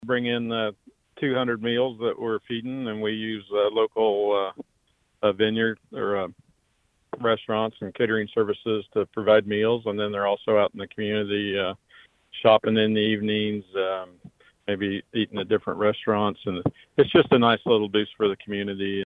Once again, the Lyon County Sheriff’s Office’s annual statewide spring training seminar was at capacity, according to Lyon County Sheriff Jeff Cope in an interview with KVOE News Friday.